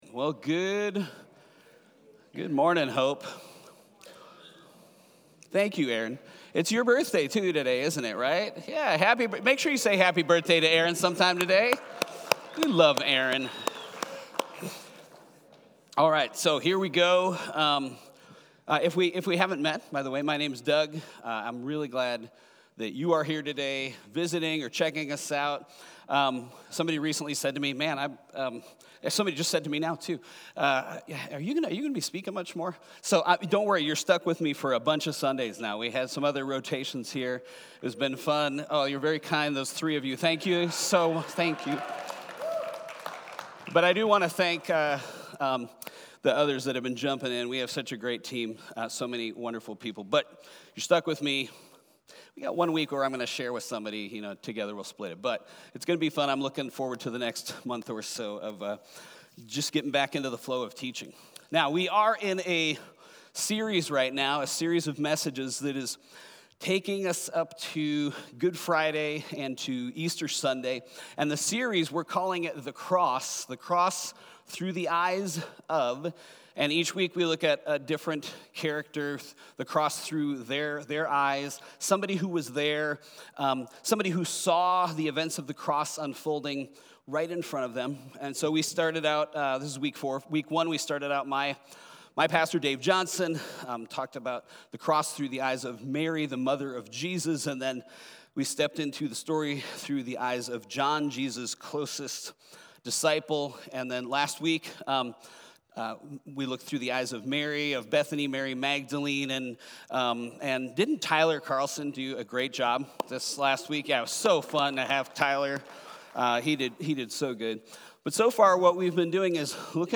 Weekly messages from HOPE Covenant Church in Chandler AZ